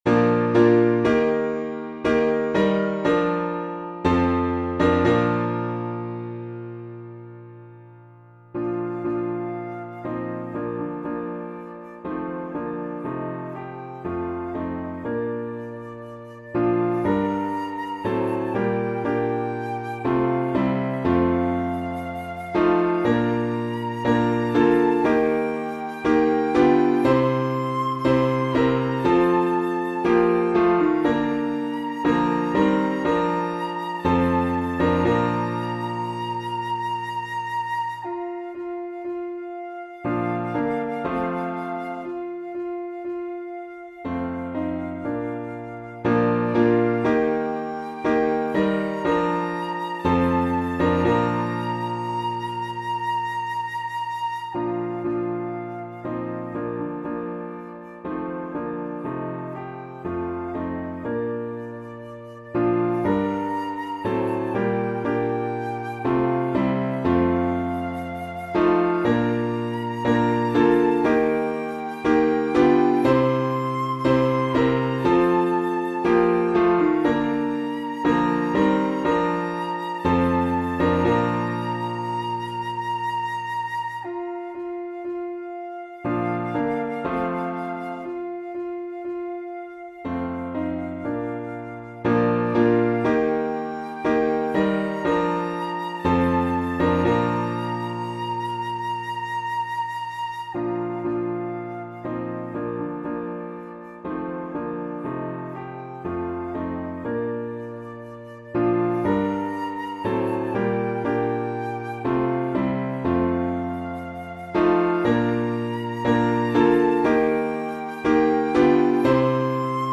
• Easy flute solo with expressive phrasing